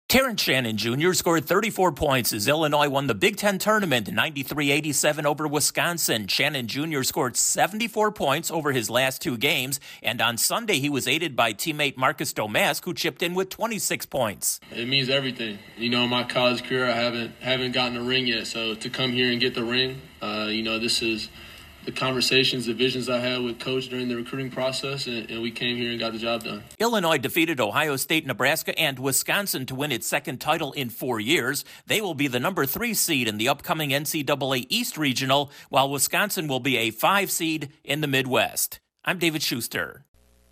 Illinois comes away with a tournament title. Correspondent